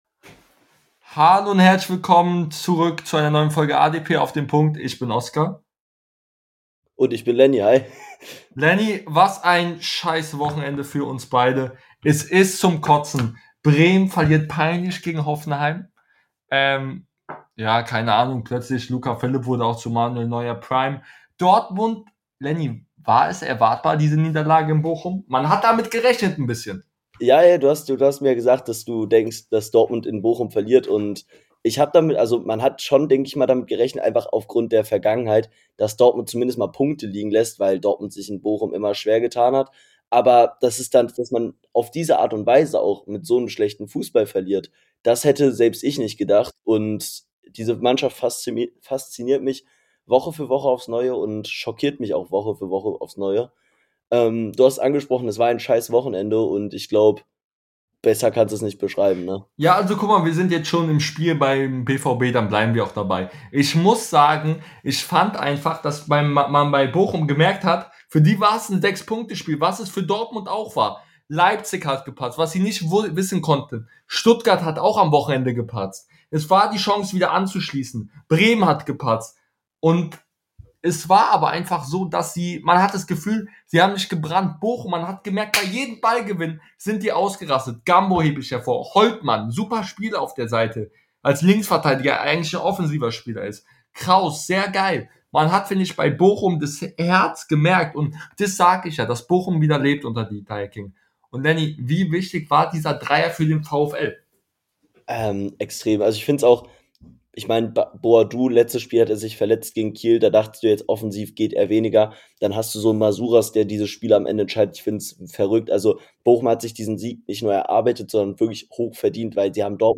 In der heutigen Folge reden die beiden Hosts über Leverkusens unbelohnte Dominanz gegen Bayern , Dortmunds erwartbare Niederlage gegen Bochum und vieles mehr